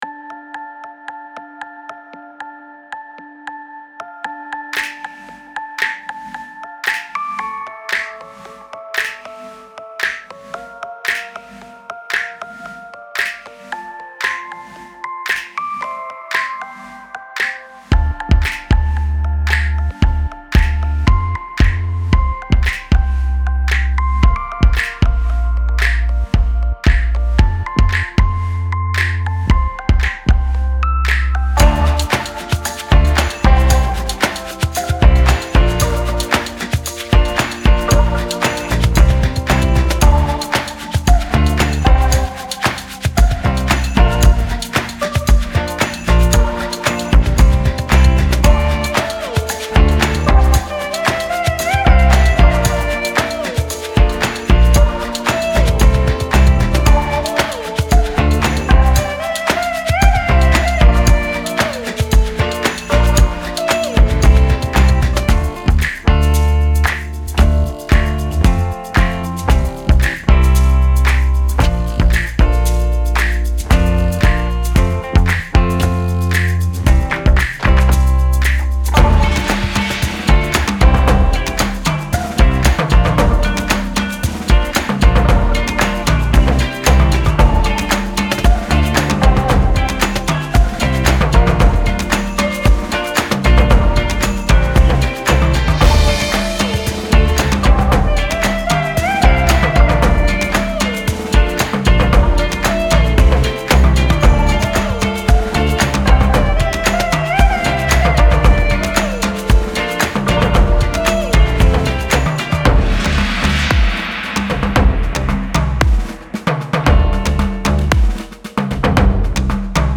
Version instrumentale playback